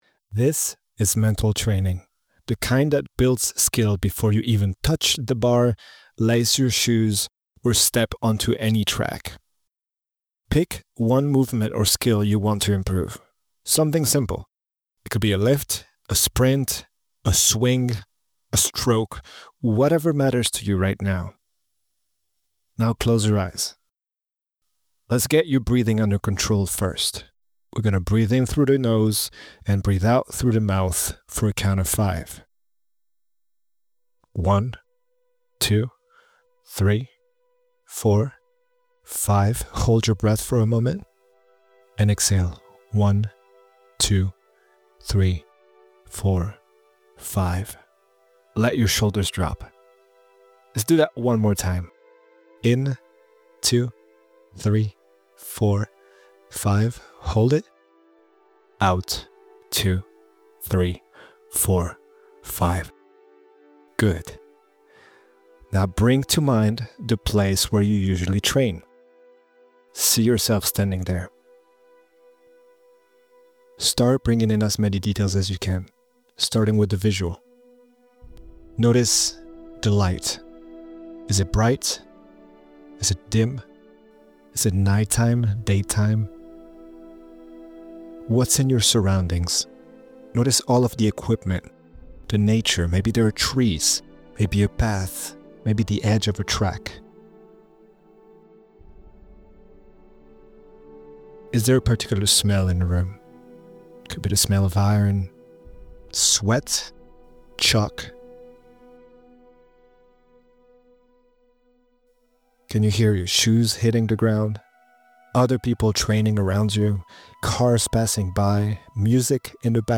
mental-training-audiotape.mp3